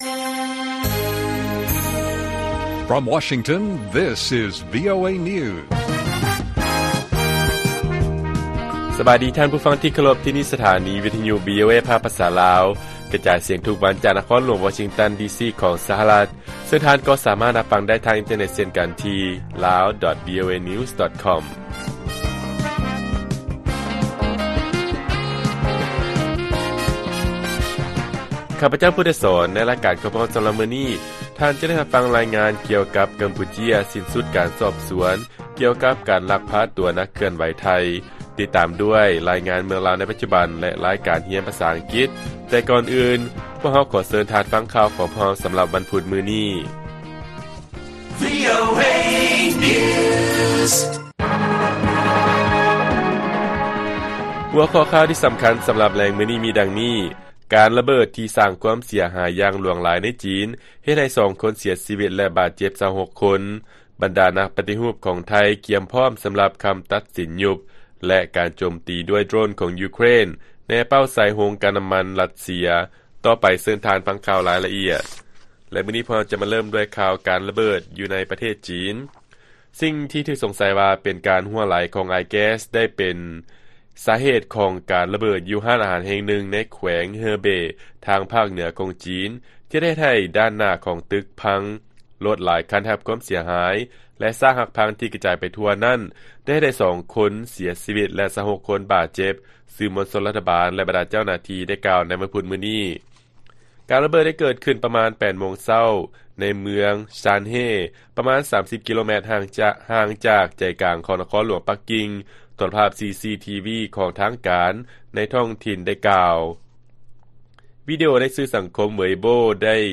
ລາຍການກະຈາຍສຽງຂອງວີໂອເອ ລາວ: ກຳປູເຈຍ ຢຸດຕິການສືບສວນຄະດີລັກພາໂຕ ນັກເຄື່ອນໄຫວຊາວໄທ